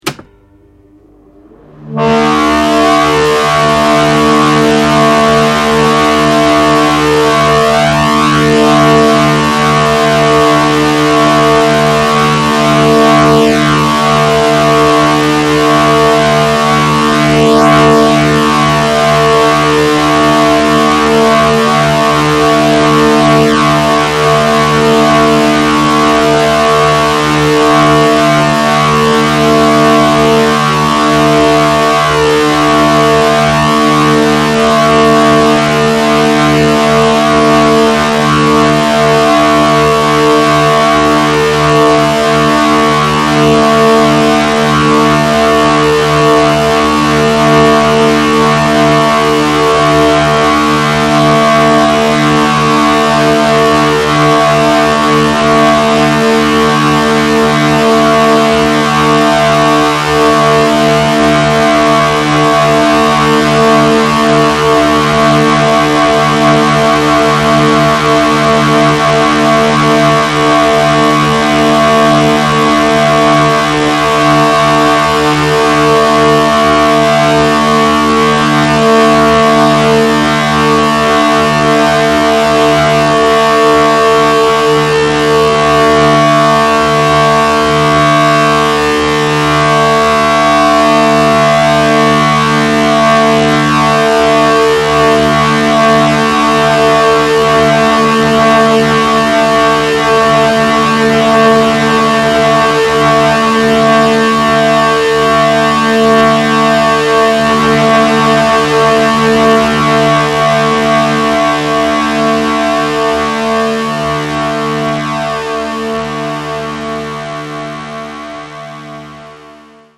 Audio: CD excerpt